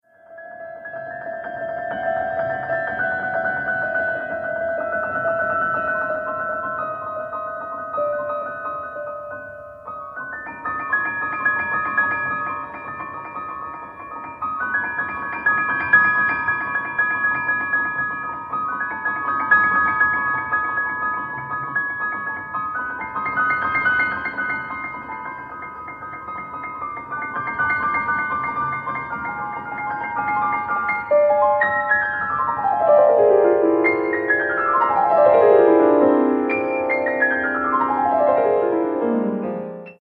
Australian pianist and composer
Classical, Keyboard